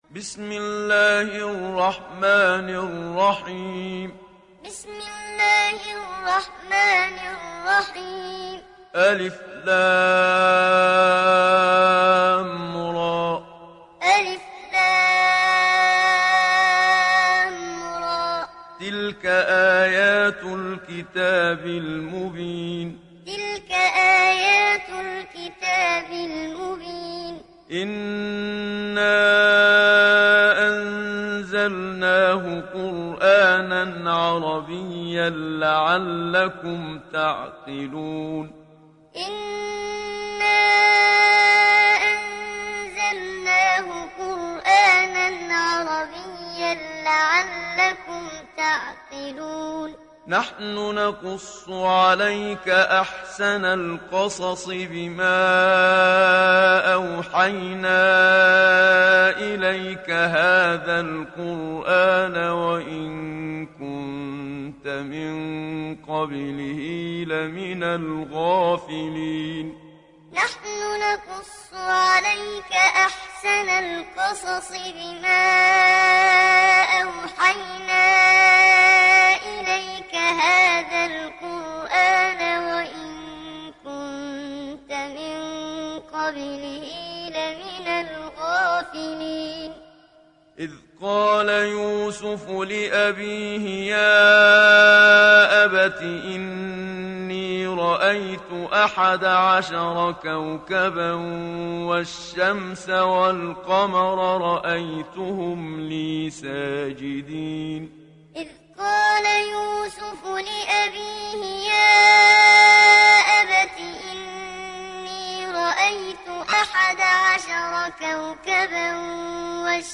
Download Surah Yusuf Muhammad Siddiq Minshawi Muallim